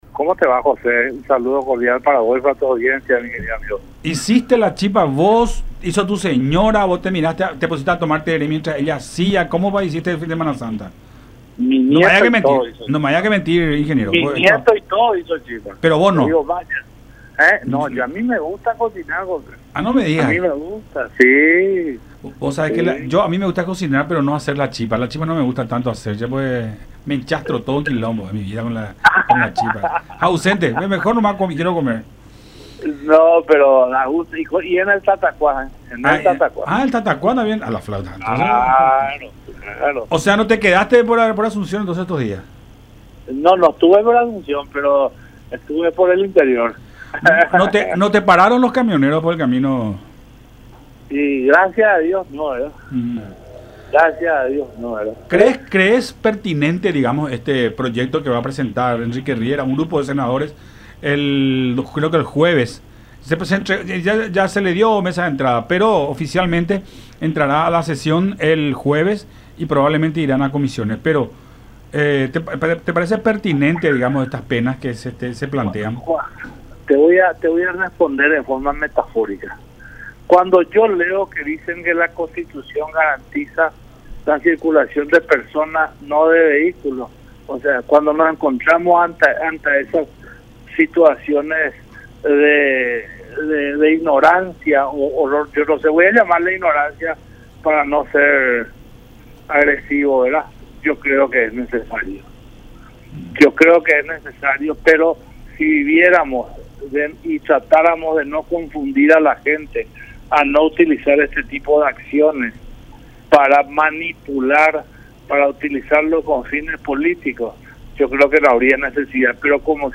en diálogo con Todas Las Voces por La Unión.